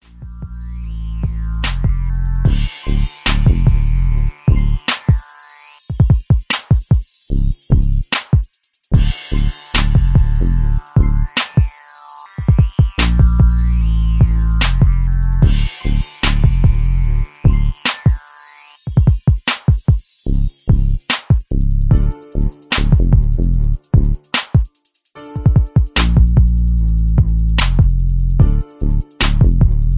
Crunk Vibe